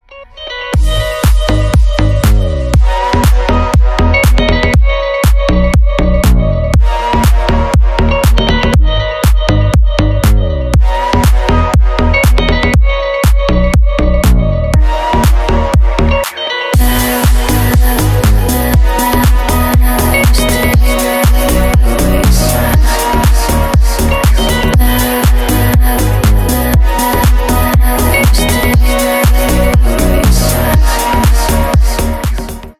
• Качество: 320 kbps, Stereo
Танцевальные
клубные